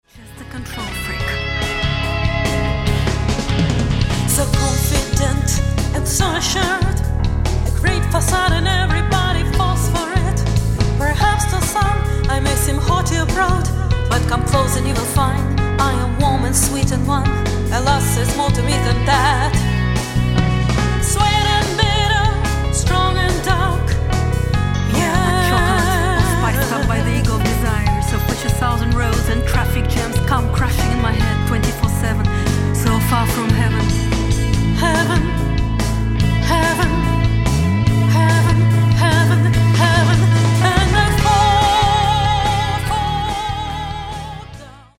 Bass
Drums
Guitar
Keyboards
Backup vocals
Mastered at Abbey Road Studios, London